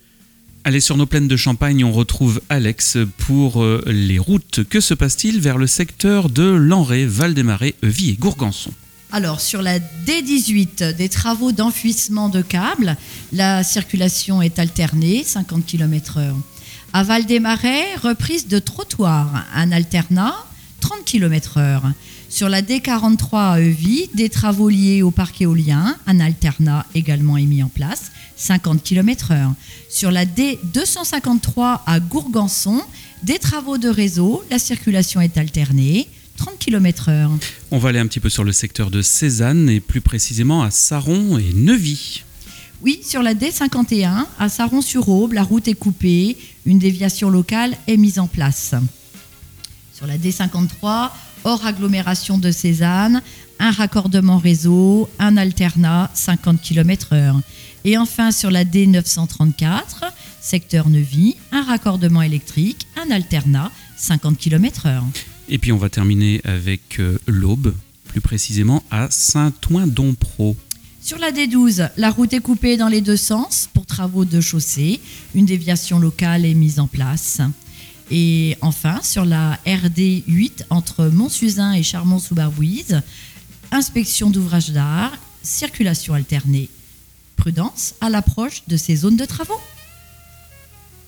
Bienvenue dans l’InfoRoute des Plaines – votre bulletin circulation du matin !